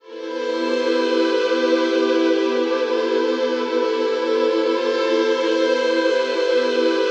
WEEPING 2 -R.wav